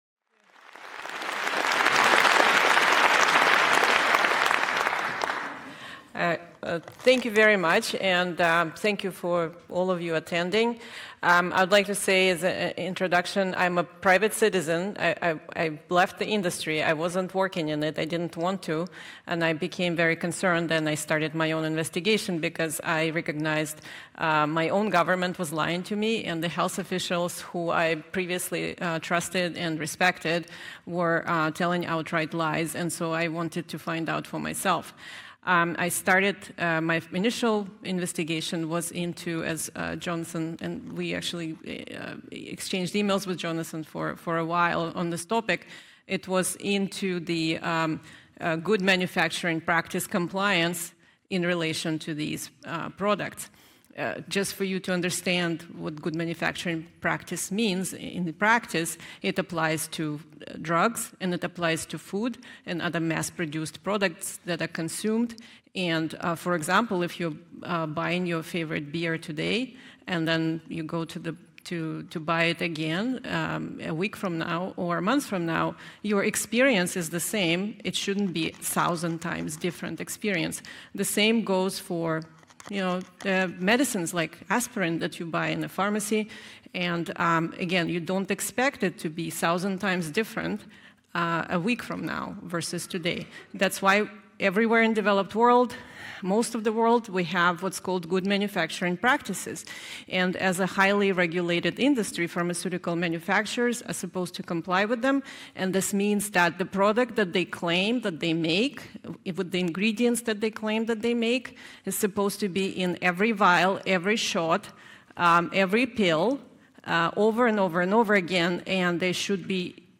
Estocolmo Enero 2023.